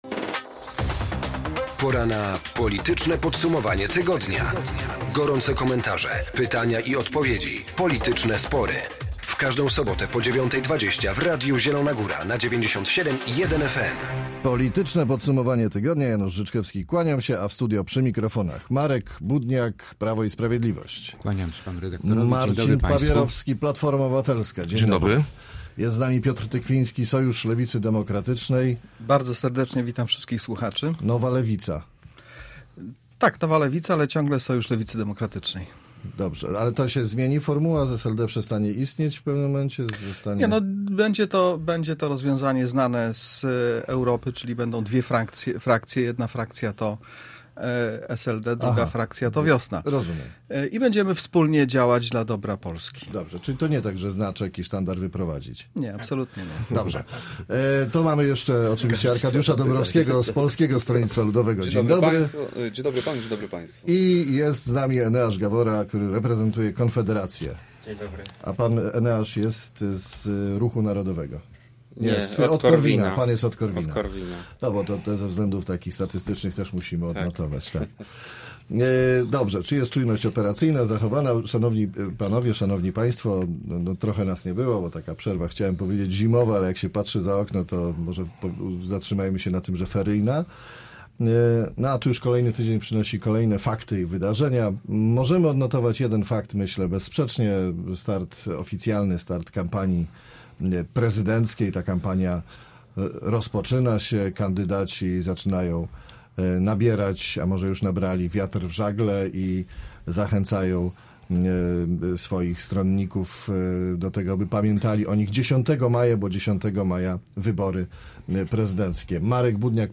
W Politycznym Podsumowaniu Tygodnia rozmawiali: